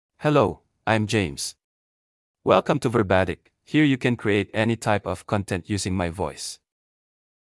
MaleEnglish (Philippines)
James — Male English AI voice
James is a male AI voice for English (Philippines).
Voice sample
James delivers clear pronunciation with authentic Philippines English intonation, making your content sound professionally produced.